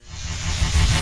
SFX / Room / Tesla / PowerUp.ogg
PowerUp.ogg